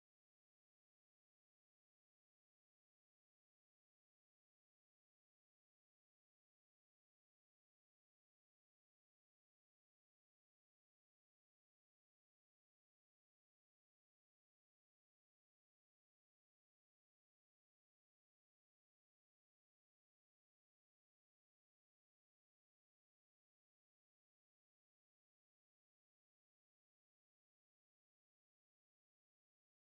STYLE: Southern Gospel